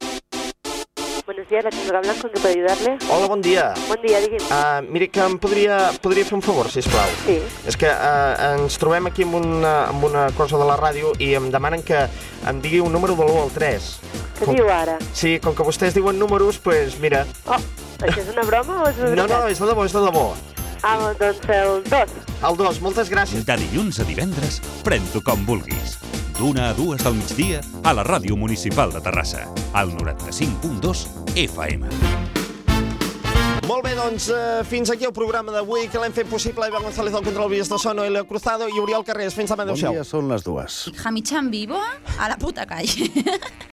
Promoció del programa
FM